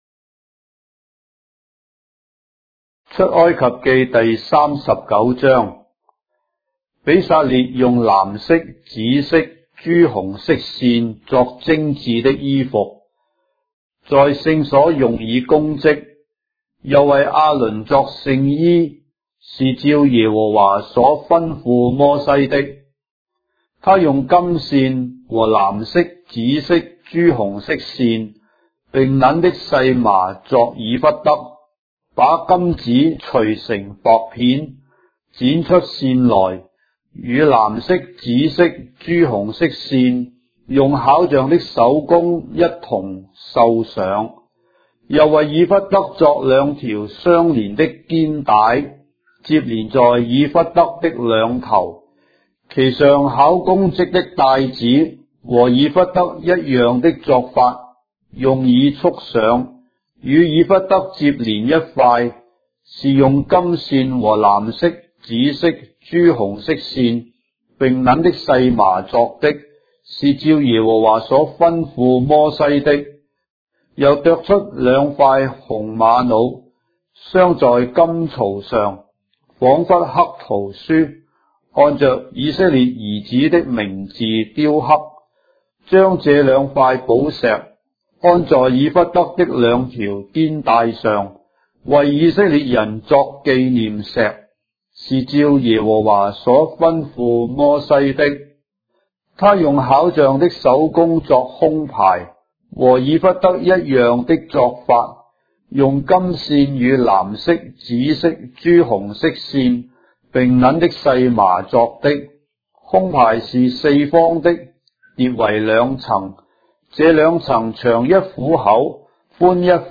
章的聖經在中國的語言，音頻旁白- Exodus, chapter 39 of the Holy Bible in Traditional Chinese